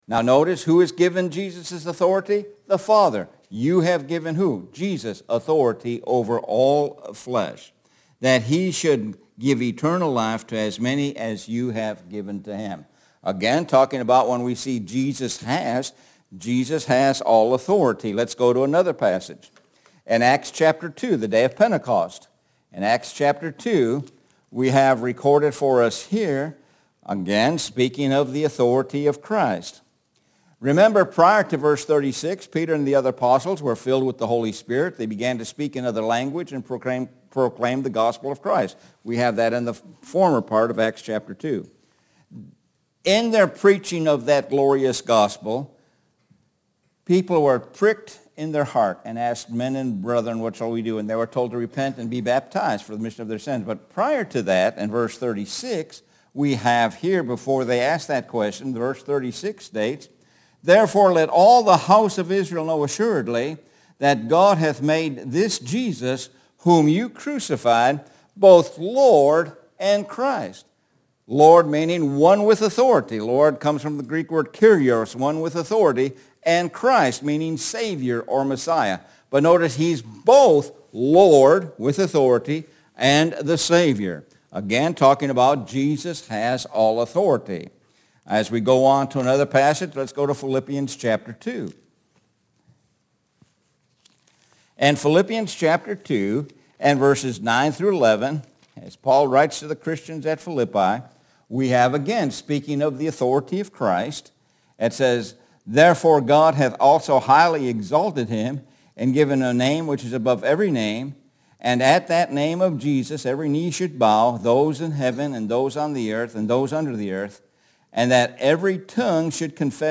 Sun Am Sermon – Jesus has, Jesus is, Jesus Will – 06.18.23